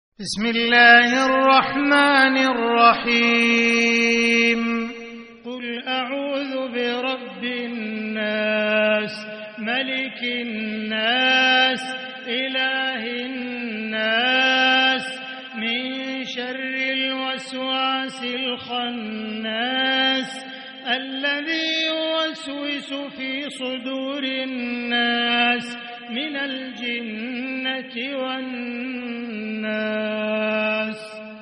سورة الناس | مصحف الحرم المكي ١٤٤٤ > مصحف تراويح الحرم المكي عام 1444هـ > المصحف - تلاوات الحرمين